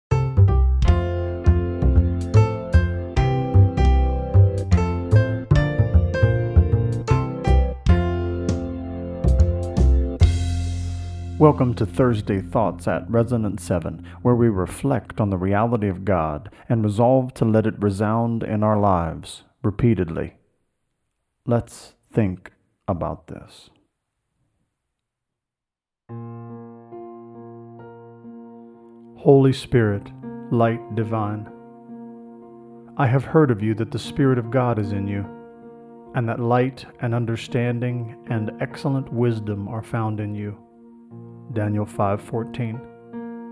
Companion to Thursday Thoughts on stanza one of this lessor known hymn.